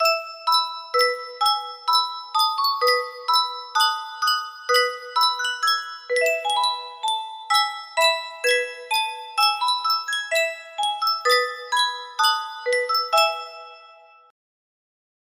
Sankyo Music Box - Onward Christian Soldiers 9X music box melody
Full range 60